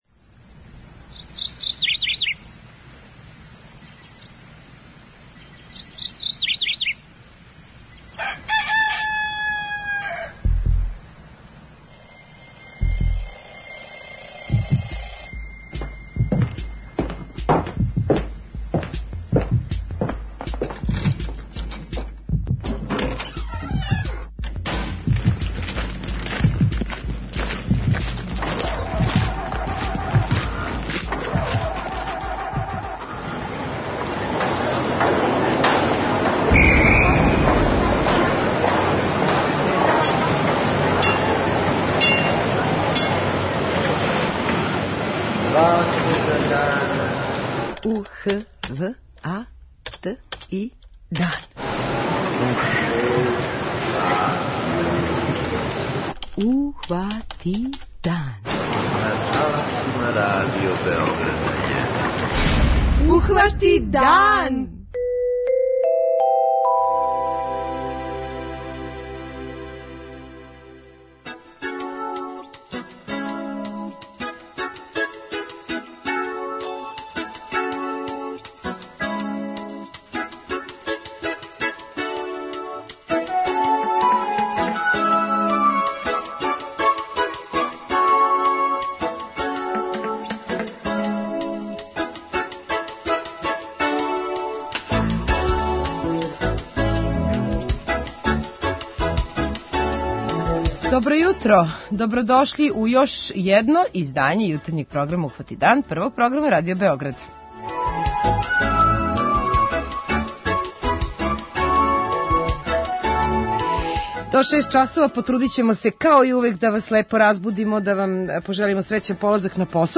преузми : 29.57 MB Ухвати дан Autor: Група аутора Јутарњи програм Радио Београда 1!